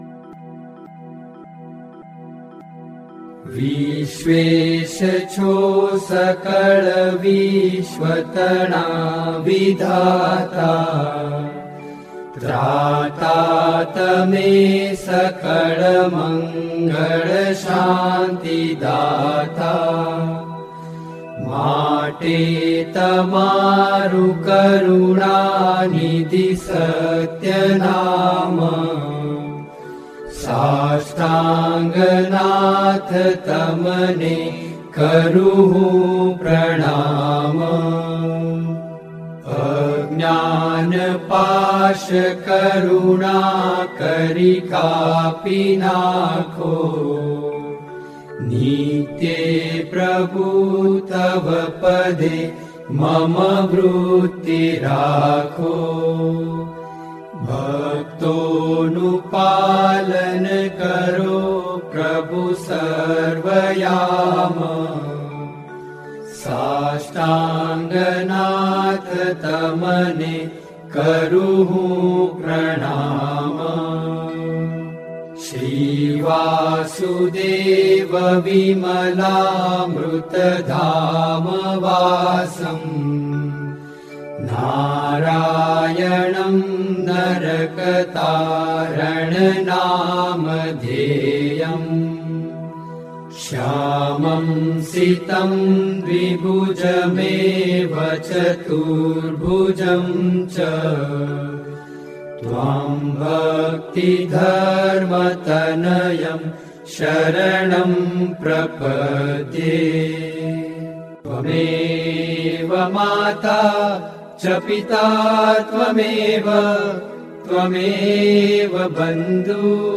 07-Dandvat1-swaminarayan-kirtan.mp3